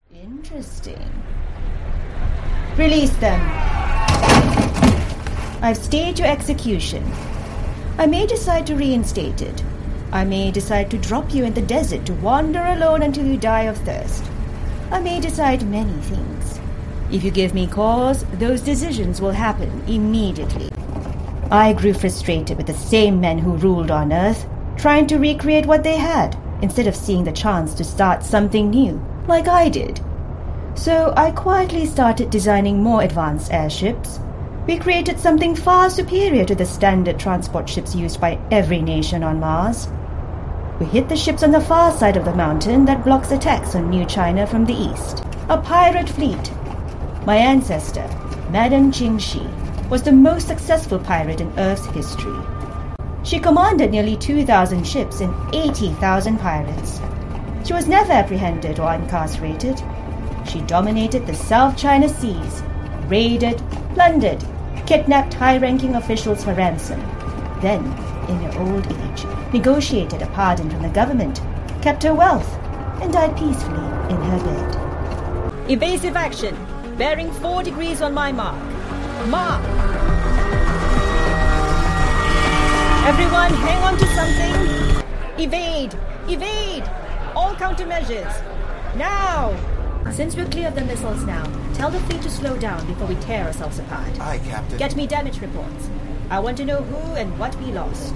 Adult (30-50) | Yng Adult (18-29)